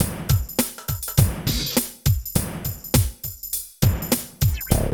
84 DRUM LP-R.wav